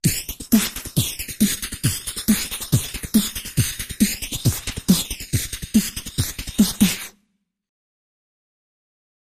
Human Beat Box, Fast, Simple, Rhythm, Type 1 - Long